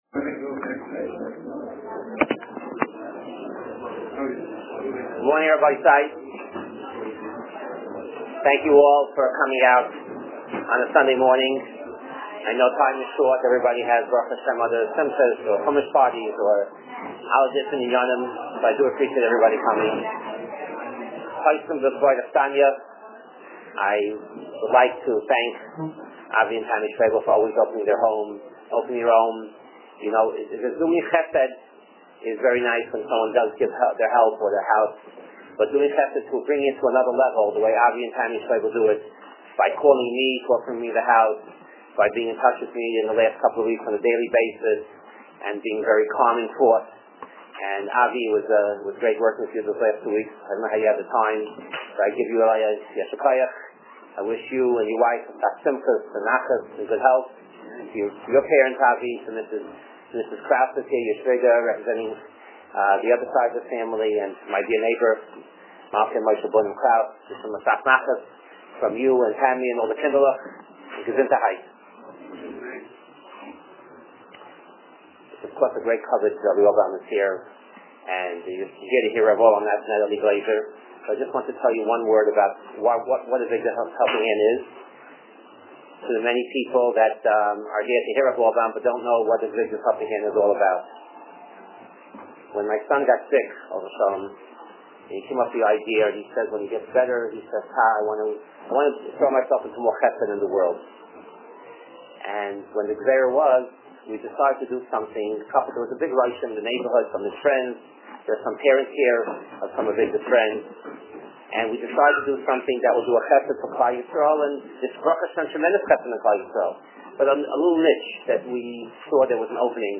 Audio of Monsey Breakfast